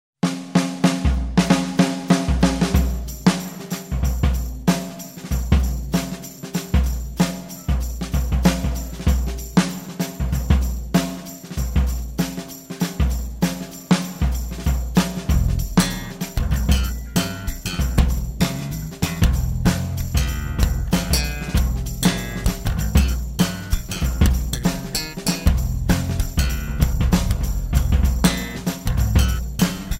Guitars
Bass
Drums
as a vehicle for soloing